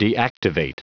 Prononciation du mot deactivate en anglais (fichier audio)
Prononciation du mot : deactivate